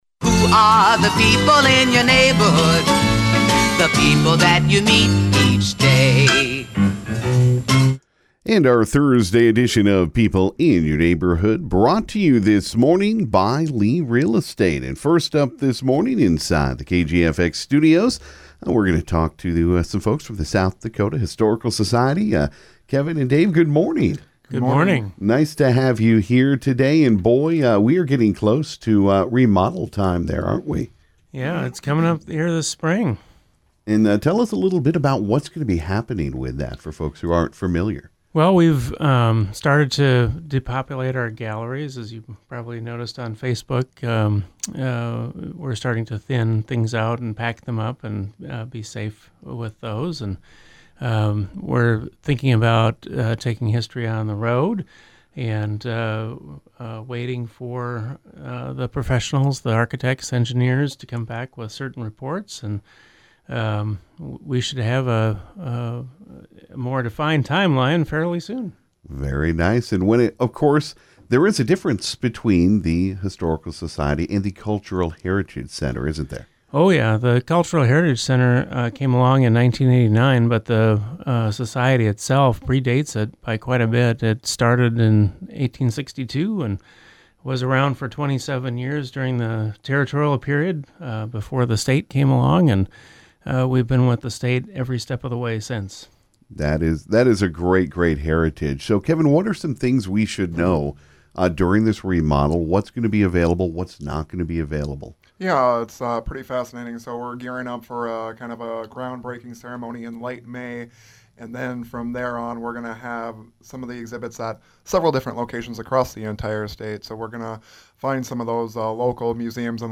Then Ft. Pierre Mayor Gloria Hanson was in to talk about snow removal around hydrants and storm drains. There is also a new location for the recycling bins in town.